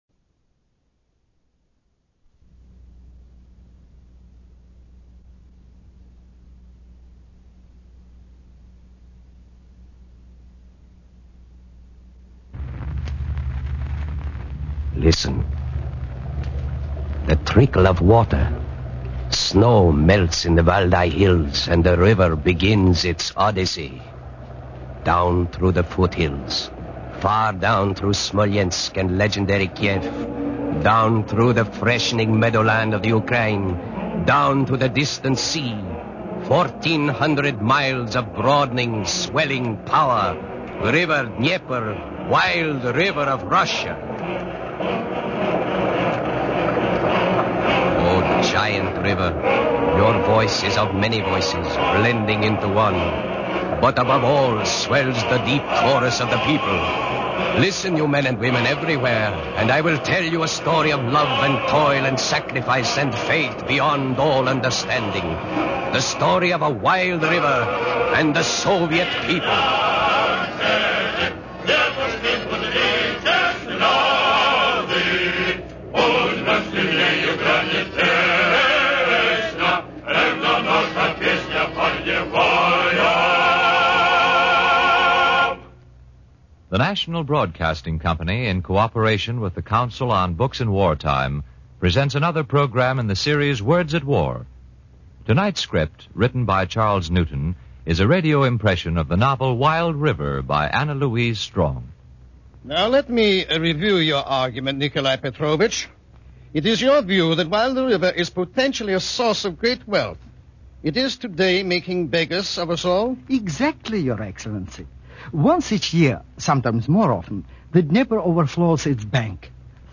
Words At War, the series that brings you radio versions of the leading war books offers this important adaptation of “Wild River,” drama of Dnieper Dam, by Anna Louise Strong.